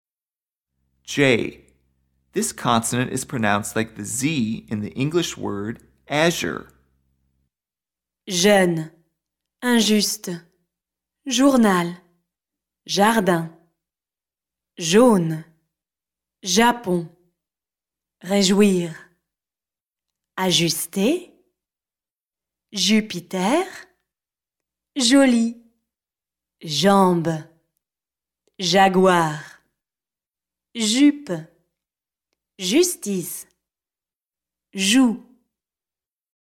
PRONONCIATION
j – This consonant is pronounced like the “z” in the English word “azure” (meaning light blue).